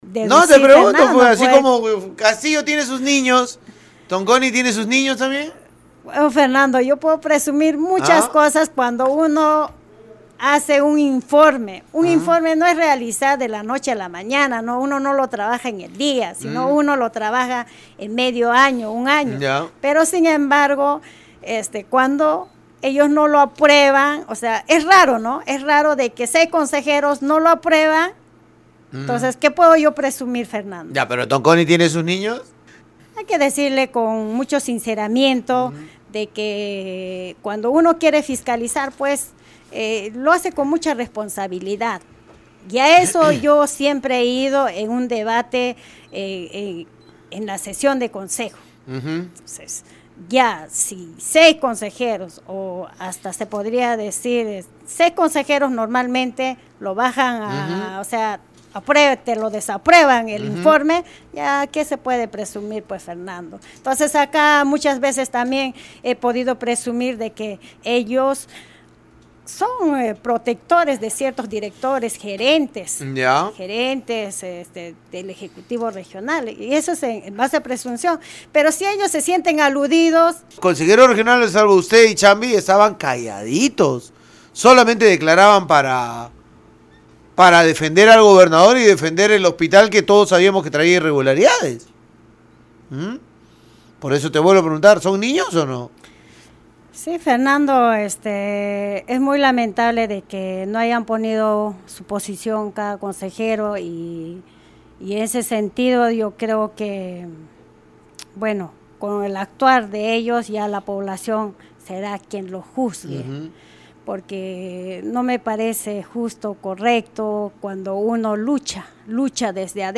La consejera regional Luz Huacapaza en conversación con Radio Uno evidenció que seis de sus colegas han mostrado respaldo a la gestión Tonconi protegiendo a la autoridad y funcionarios de confianza designados por este.